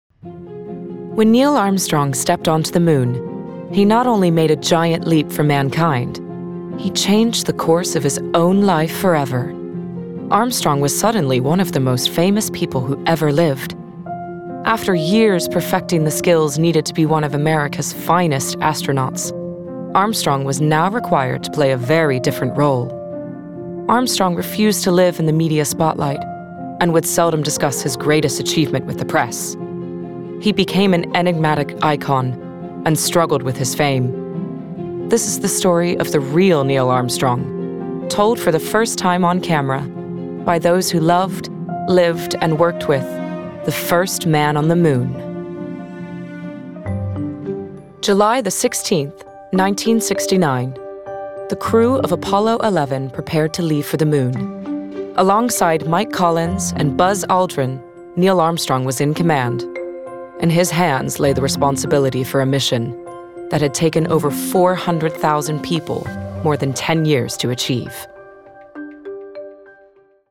Gender Female
Narration & Documentary Clips
Informative, Smooth, Confident https